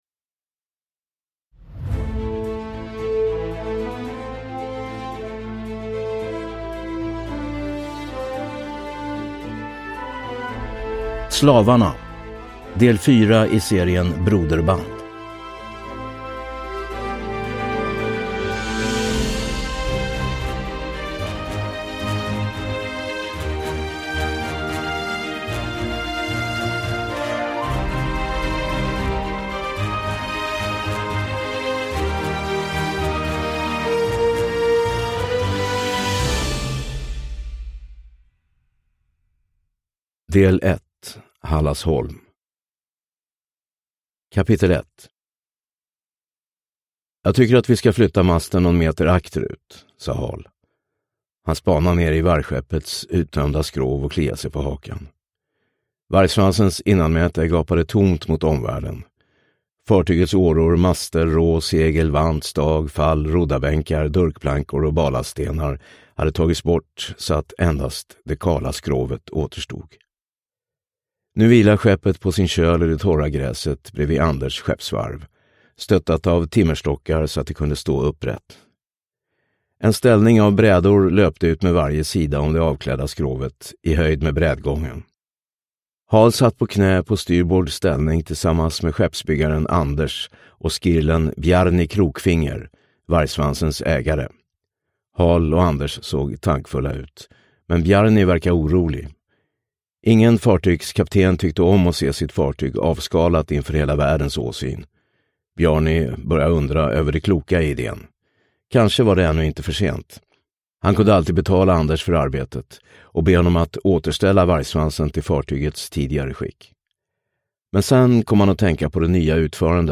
Slavarna – Ljudbok
Uppläsare: Tomas Norström